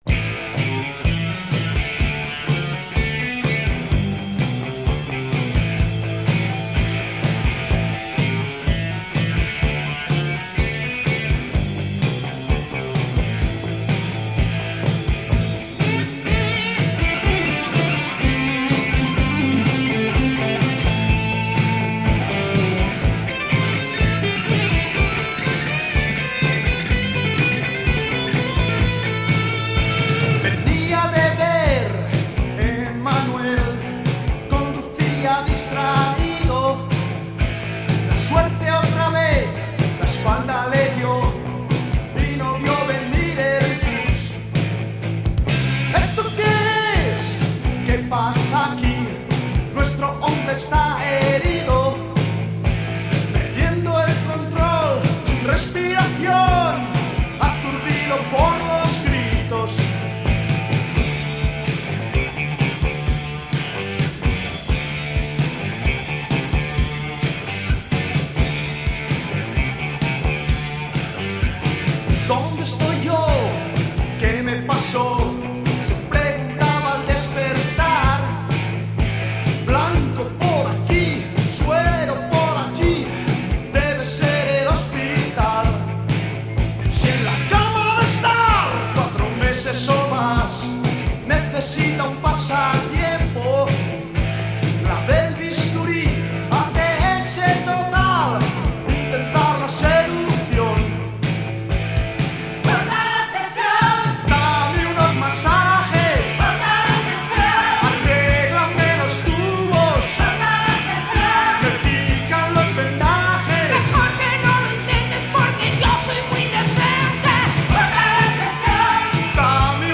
La voz femenina